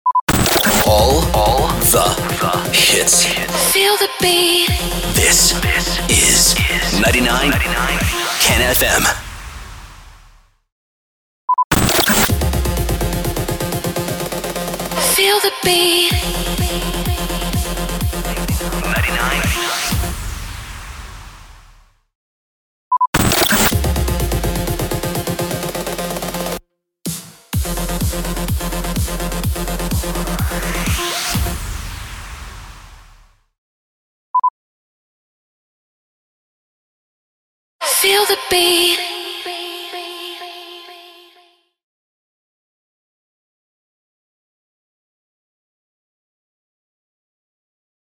770 – SWEEPER – FEEL THE BEAT